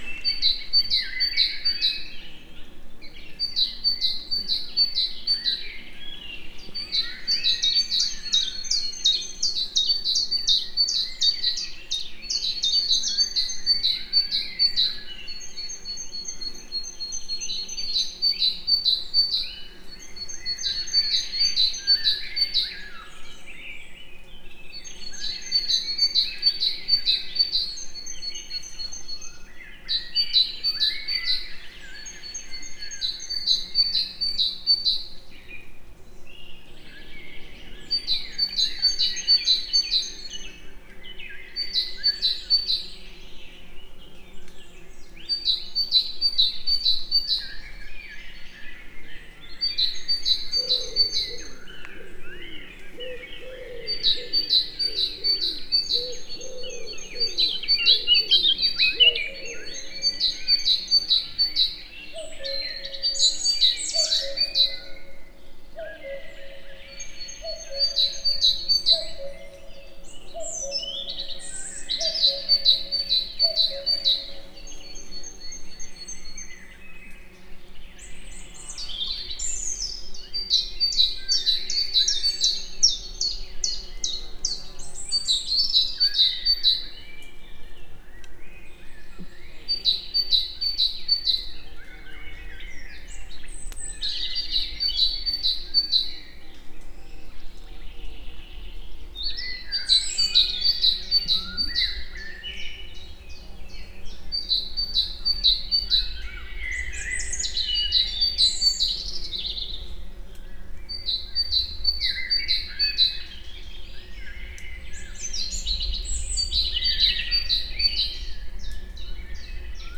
masmadarakesrovarokis_egerturistahaz02.52.WAV